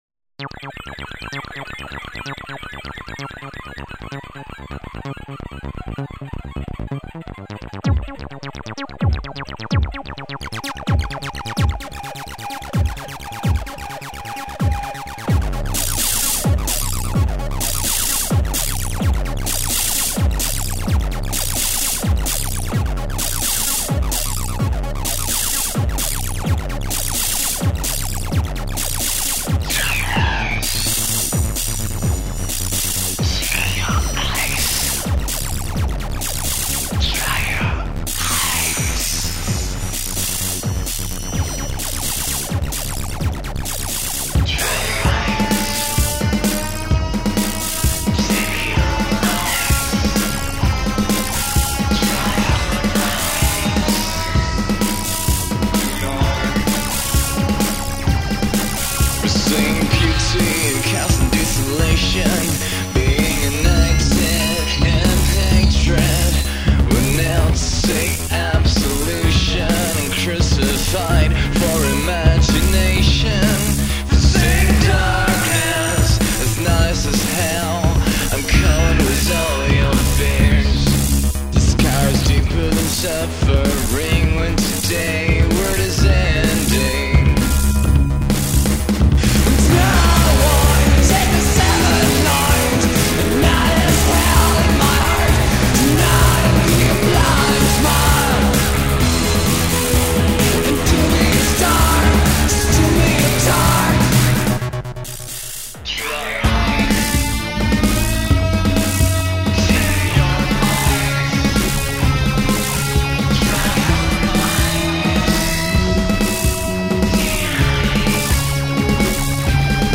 Vocals, bass
Keyboards, Synths
Guitars, prog